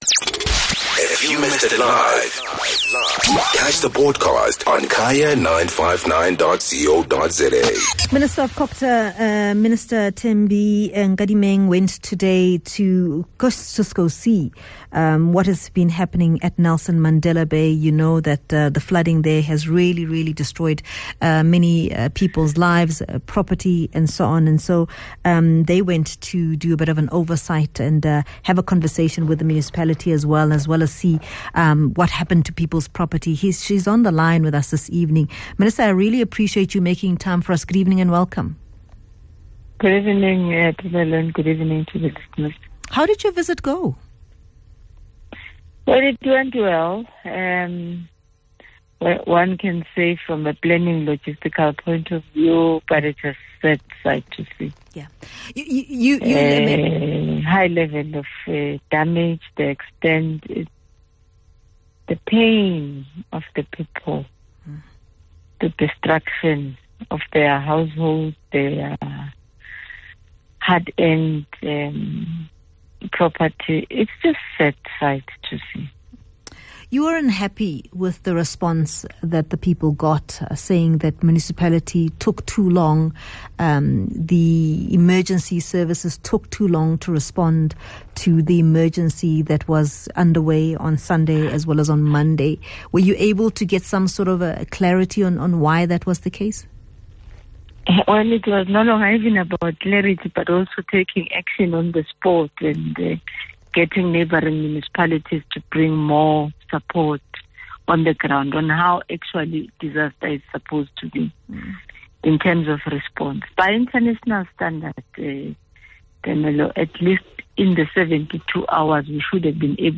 Guest: Thembi Nkadimeng - COGTA Minister